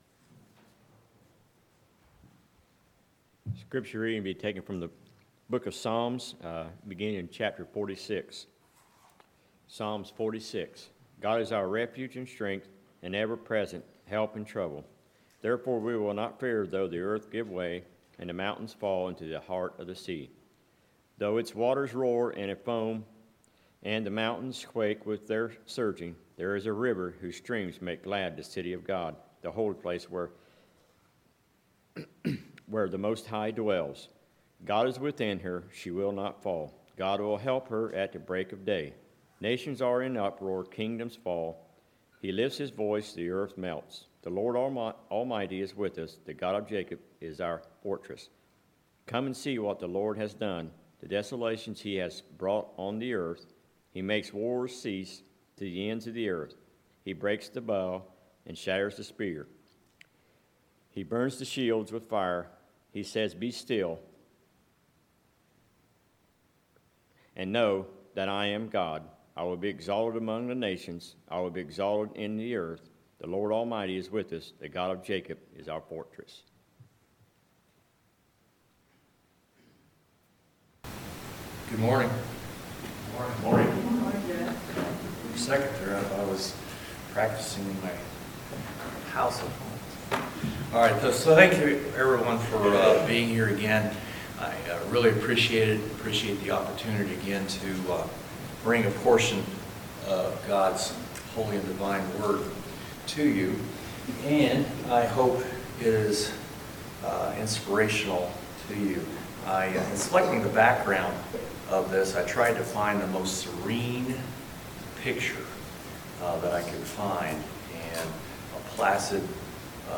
Sermons, June 7, 2020